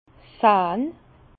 Bas Rhin sähn Haut Rhin sähn
Prononciation 67 Herrlisheim